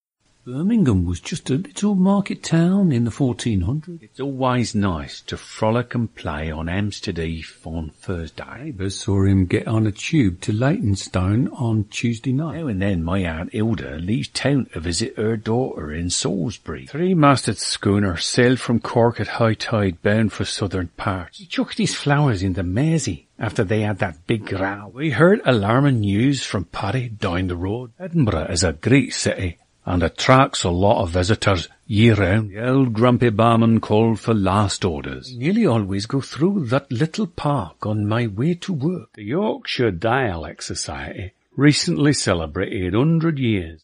Dialects: Birmingham, Cockney, Estuary, Hampshire, Irish, Liverpool, Northern Ireland, Scottish, Standard British (RP), Welsh, Yorkshire.
British-and-Irish-composite.mp3